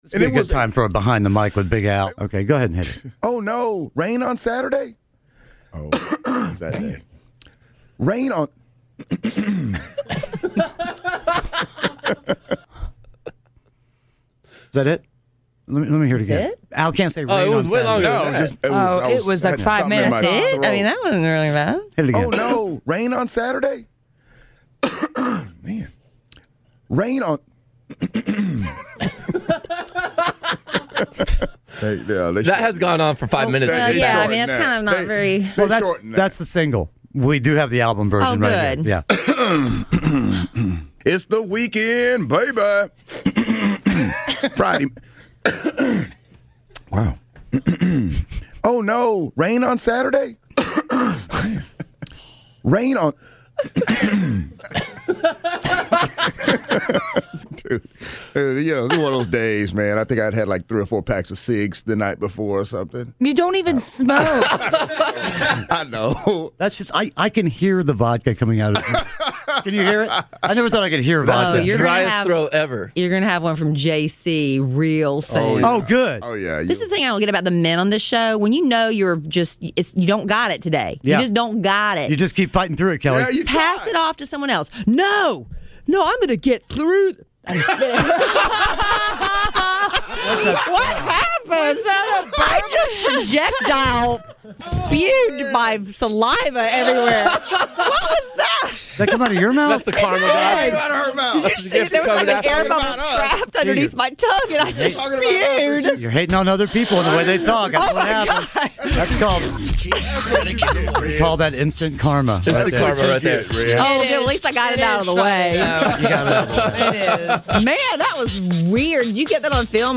During our "Behind the Mic" segments, we play audio from when our cast members mess up recording things for our show.